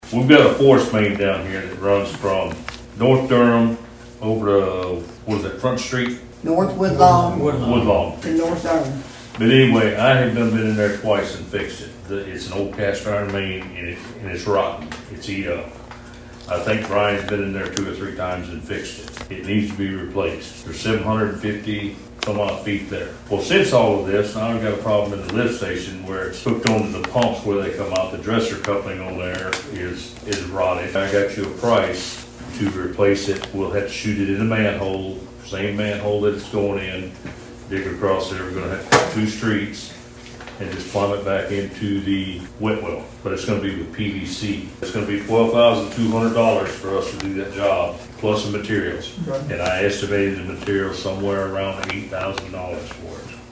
At Sharon’s  City Board meeting, dilapidated sewer lines were brought to the board’s attention.
A representative from the Sharon Water System came to explain to the board what could be done concerning the lines.